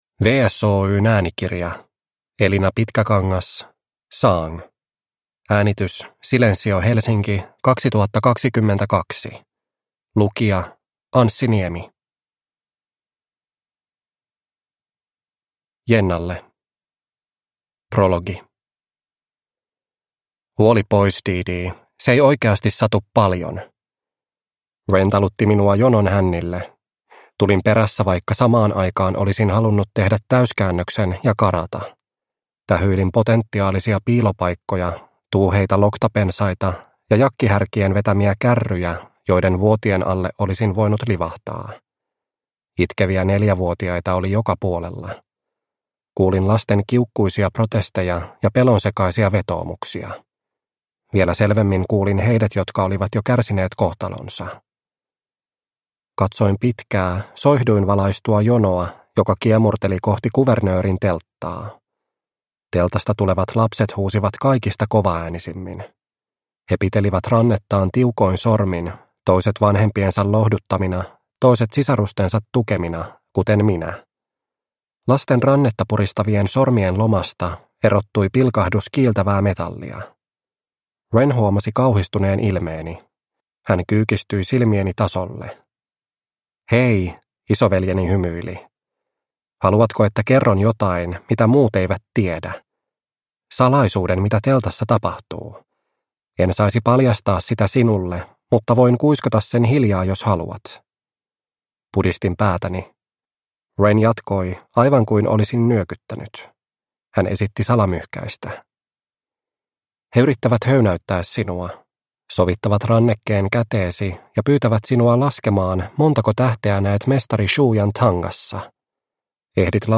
Sang – Ljudbok – Laddas ner